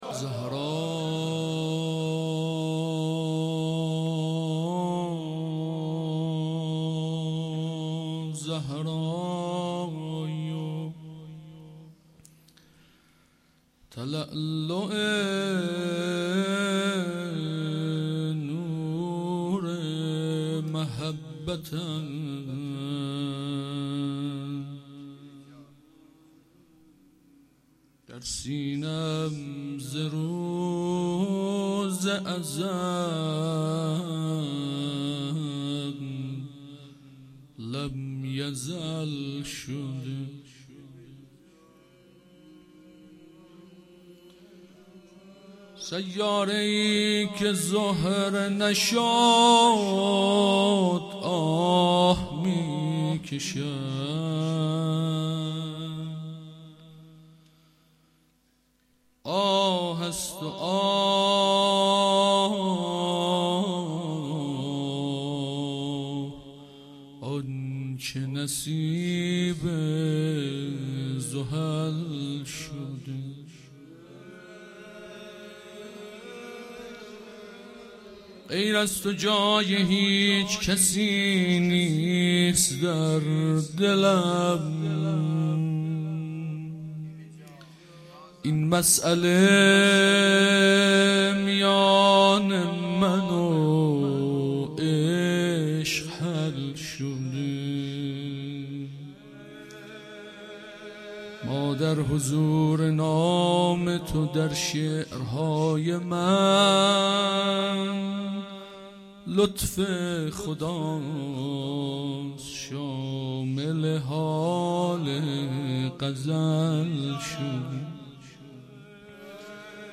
roze.mp3